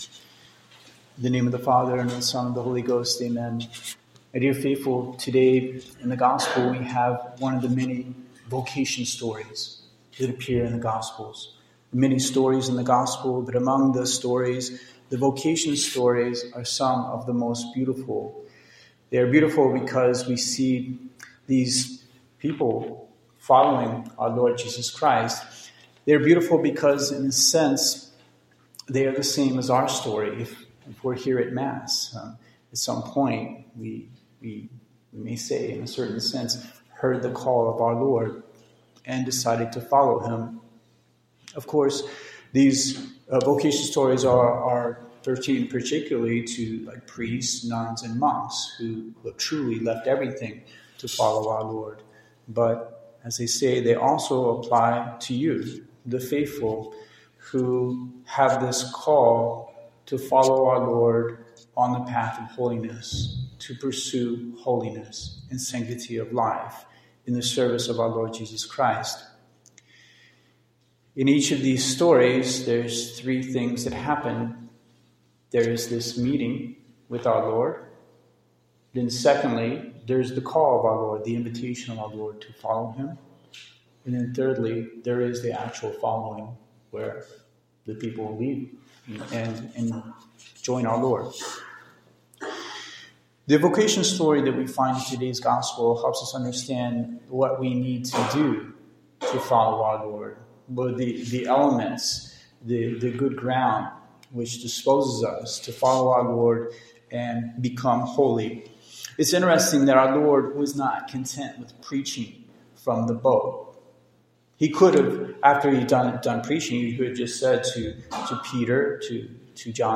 Holiness and the Reverence of the TLM, Sermon